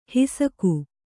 ♪ hisaku